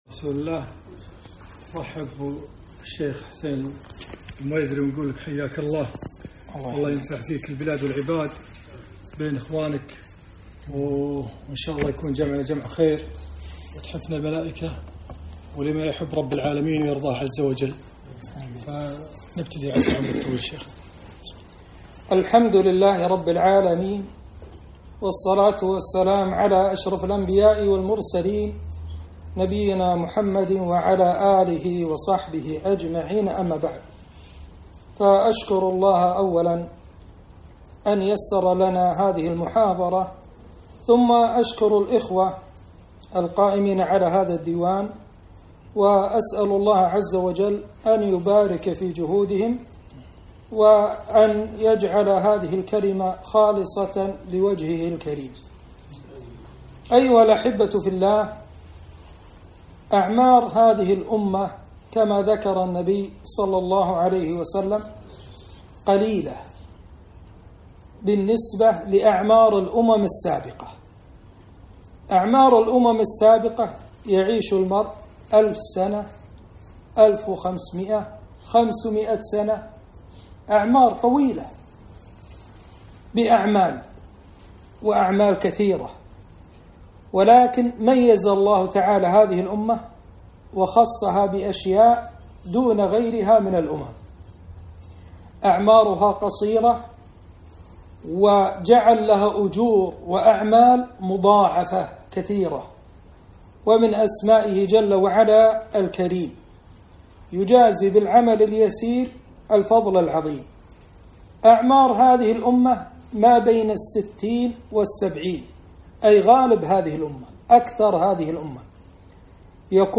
محاضرة - أجور عظيمة بأعمال يسيرة